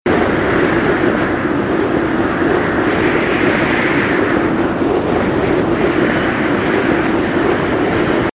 Roblox Falling Sound Effect Free Download